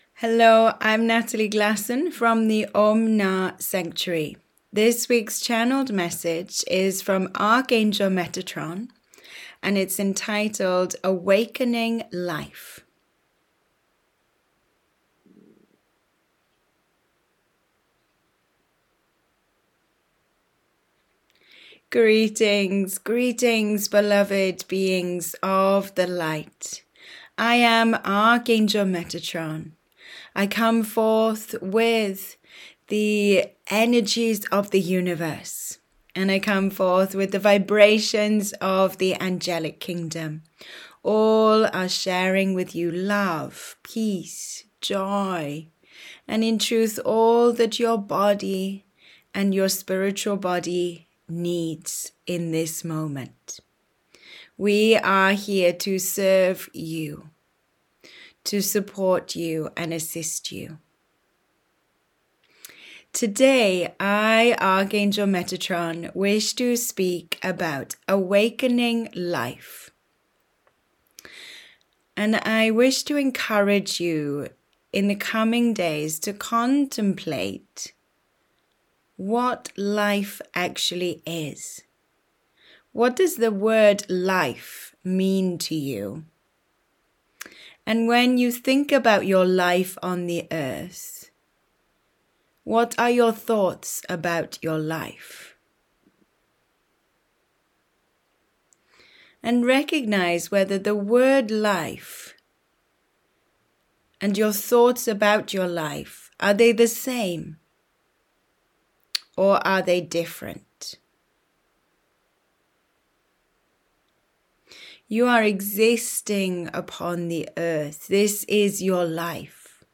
Channeled Message